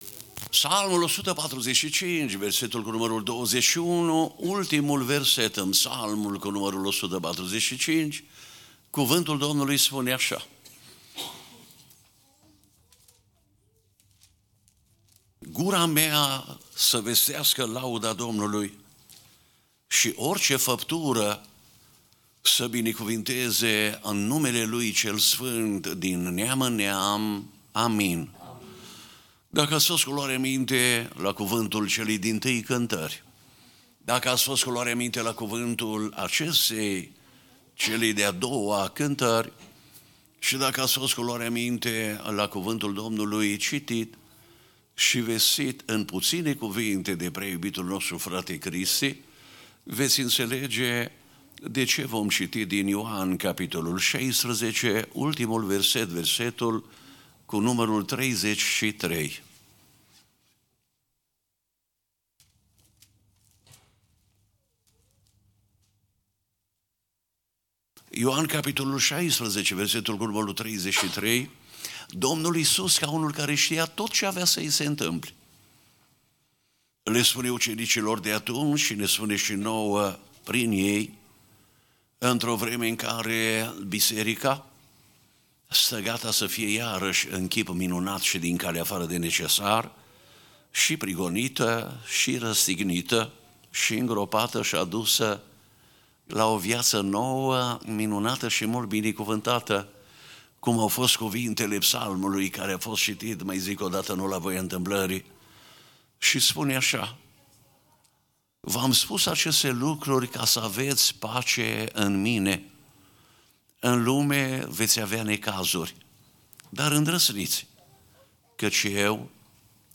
Passage: Psalmul 145:21 Tipul Slujbei: Seara Rugaciune « Disciplina Darniciei/Zeciuielii